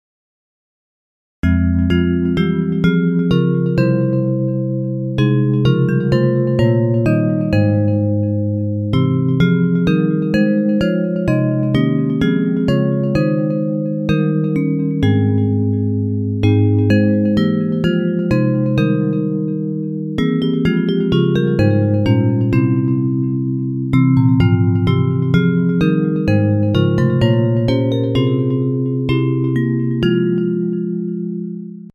Bells Version
Hebrew Melody